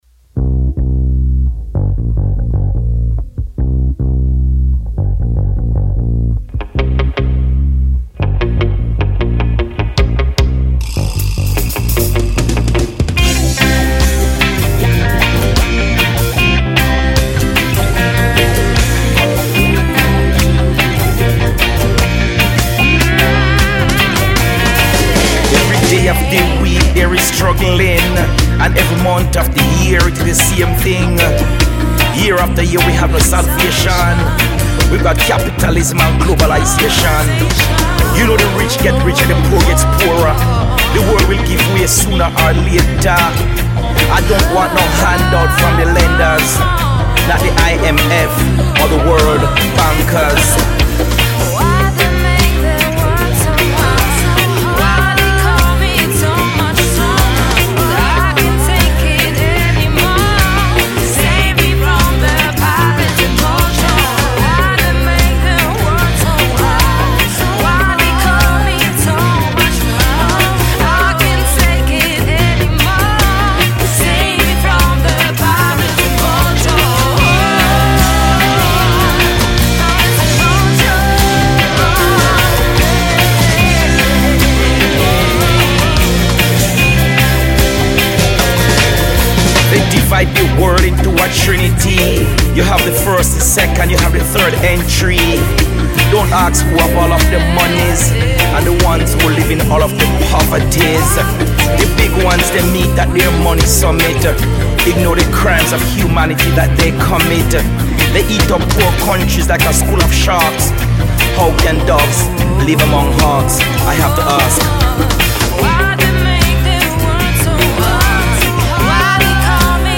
fusion, dub-rock, reggae band